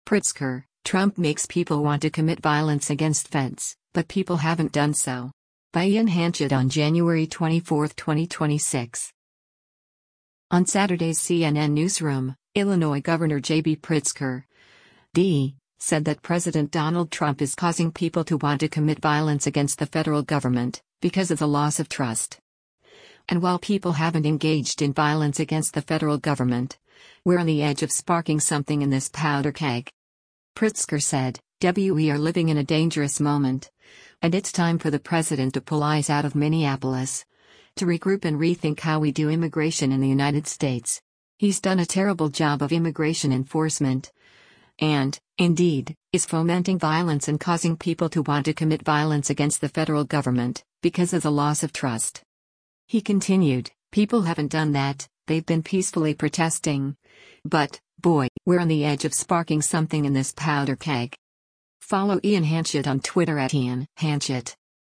On Saturday’s “CNN Newsroom,” Illinois Gov. JB Pritzker (D) said that President Donald Trump is “causing people to want to commit violence against the federal government, because of the loss of trust.”